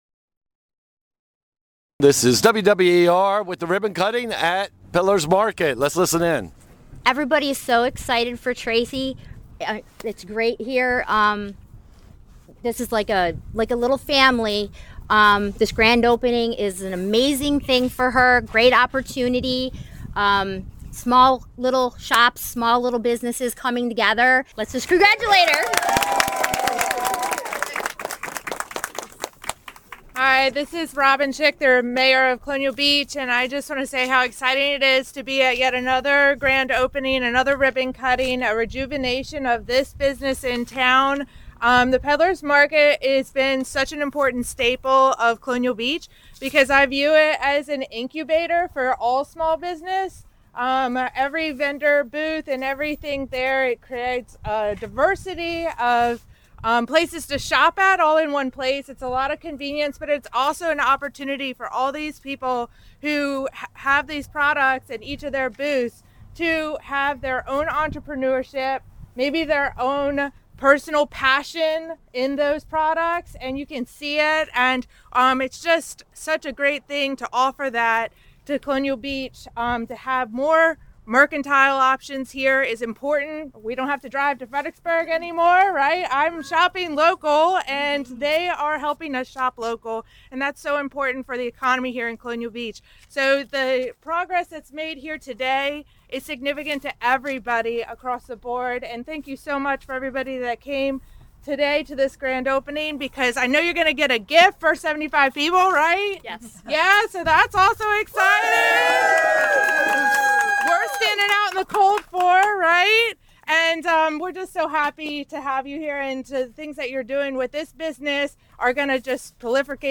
A small crowd braved the cold to watch the ribbon cutting for Peddler’s Market.